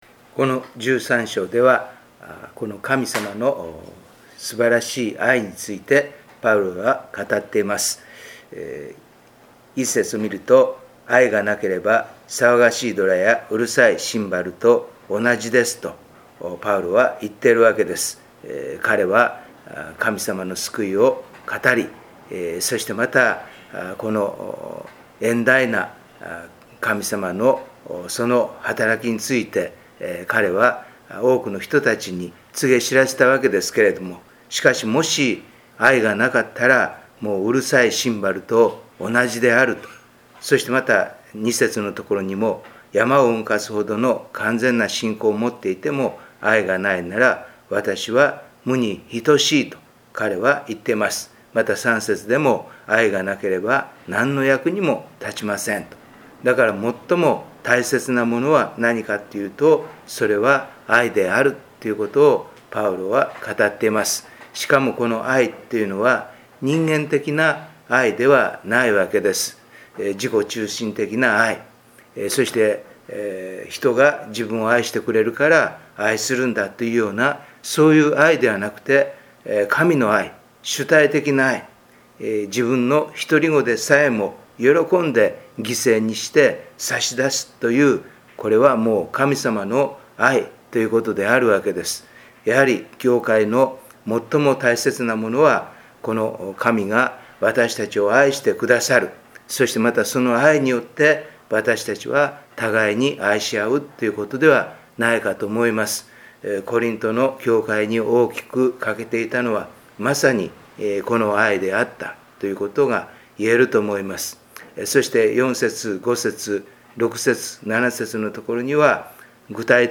7月のデボーションメッセージ